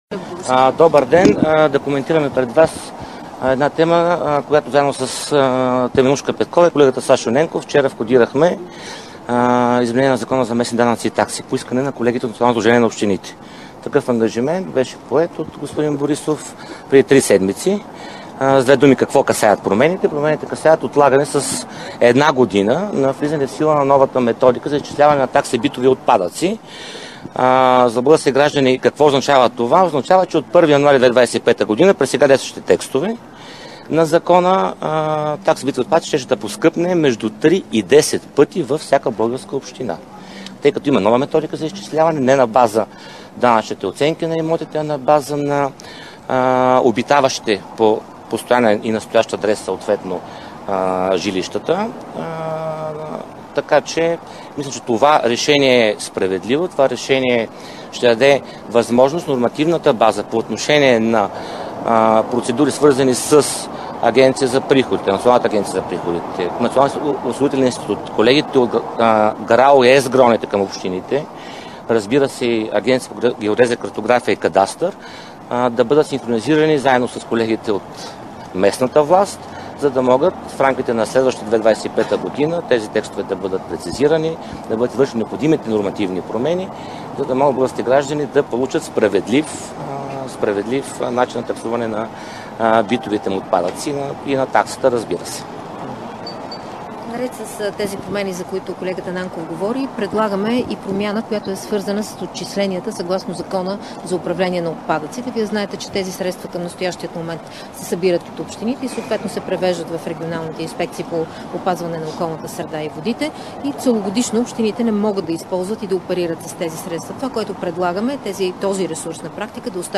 Директно от мястото на събитието
11.00 - Брифинг на Николай Нанков и Теменужка Петкова от ГЕРБ във връзка с предложения за промени в закона за местните данъци и такси. - директно от мястото на събитието (Народното събрание)